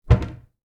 Cabinet Close Sound Effect 10 The sound of a cabinet door being closed (slight vibration)
cabinet-door-close-10.wav